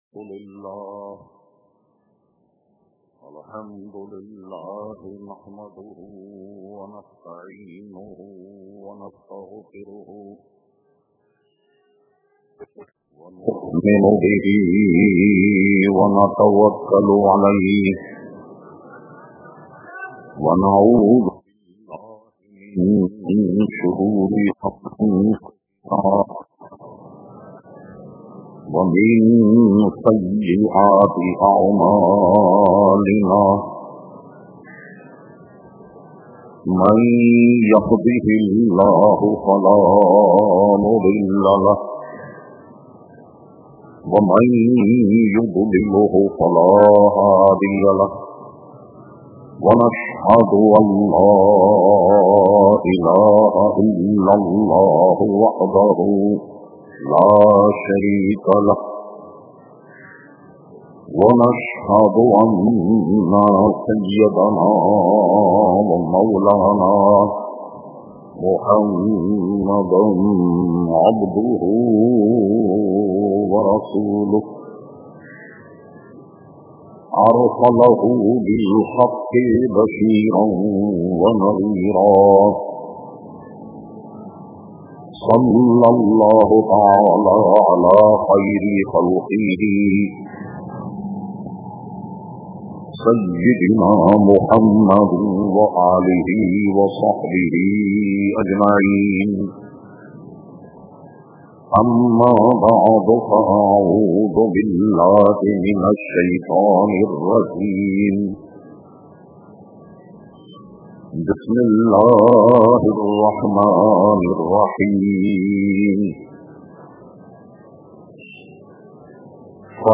Category : Speeches | Language : Urdu